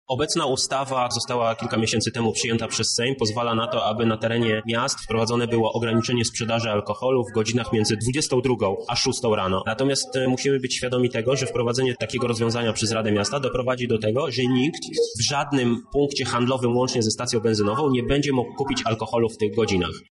O tym, na jakiej podstawie byłoby możliwe ograniczenia sprzedaży mówi przewodniczący klubu radnych Platformy Obywatelskiej, Michał Krawczyk: